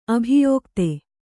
♪ abhiyōkte